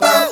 Hip Vcl Kord-E.wav